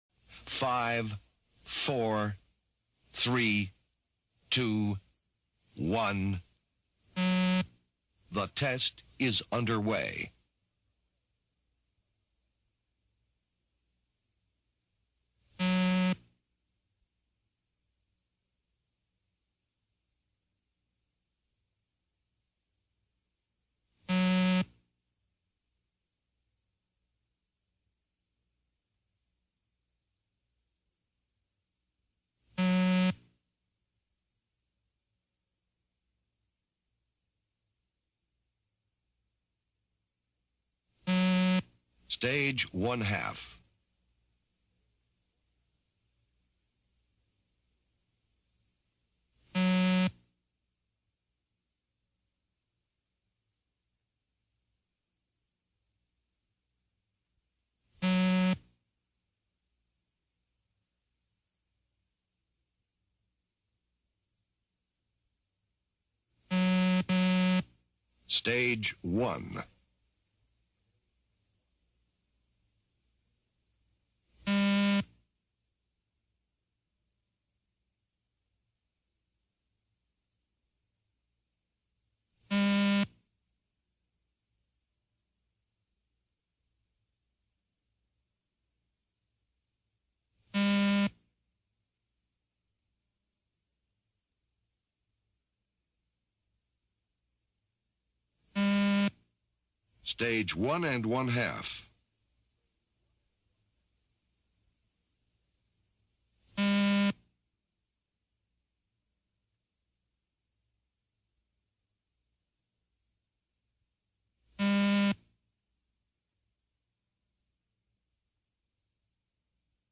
Beep Test Audio.mp3